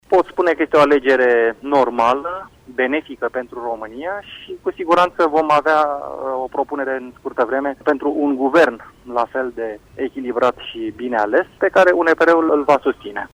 Vicepreşedintele UNPR, Valeridu Steriu a precizat pentru postul nostru de radio că fostul comisar european este o alegere bună care va asigura stabilitatea ţării: